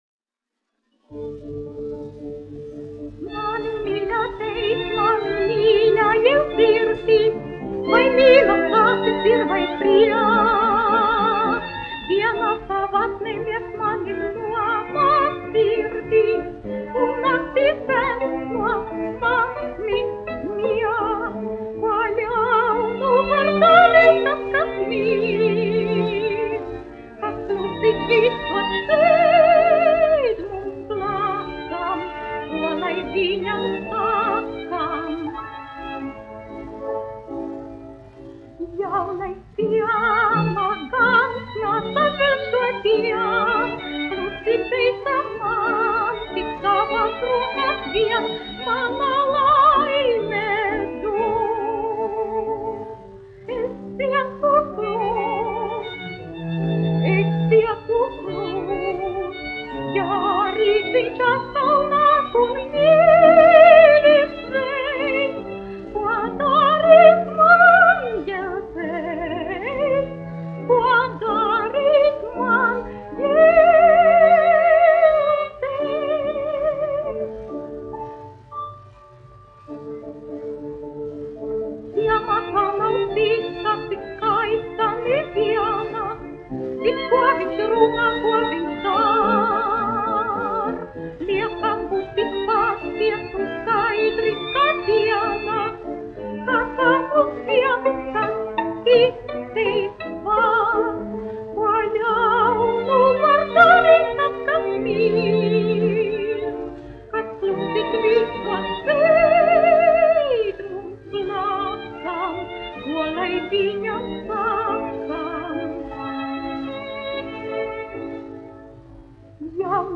1 skpl. : analogs, 78 apgr/min, mono ; 25 cm
Dziesmas (augsta balss)
Latvijas vēsturiskie šellaka skaņuplašu ieraksti (Kolekcija)